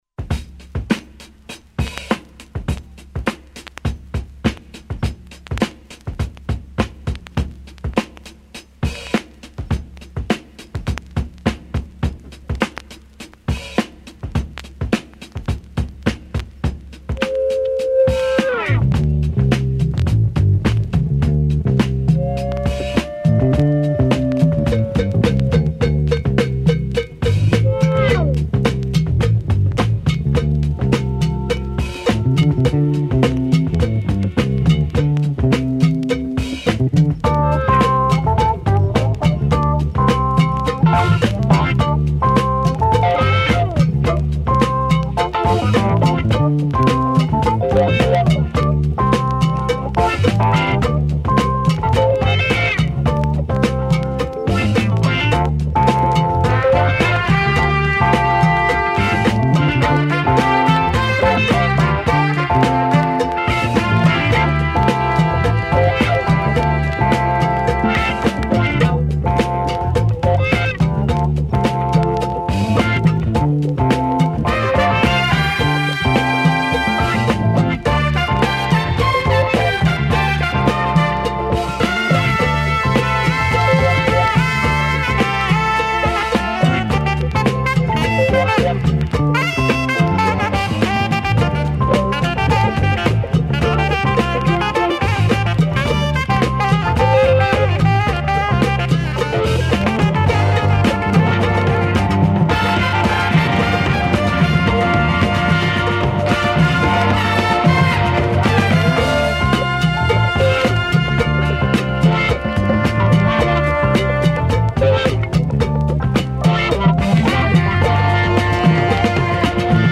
I was lucky enough to do a couple of sets, and thought I’d share my first mini-set with you guys here.